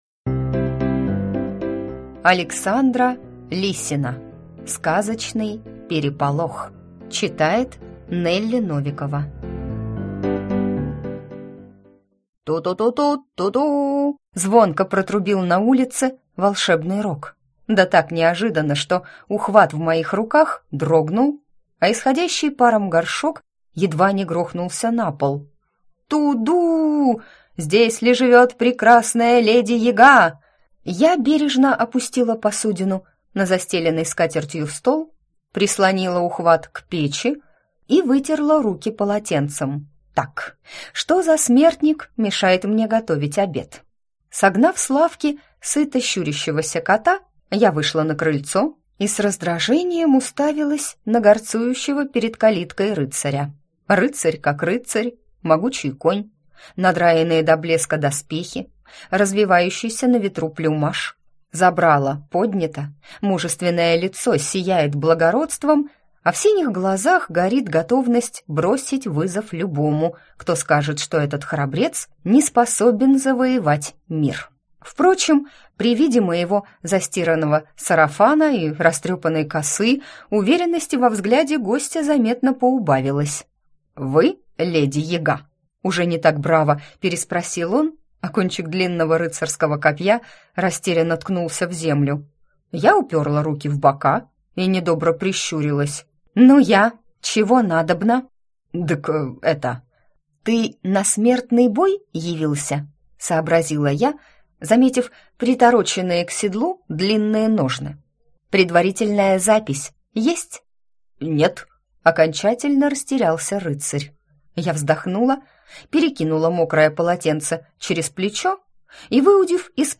Аудиокнига Сказочный переполох | Библиотека аудиокниг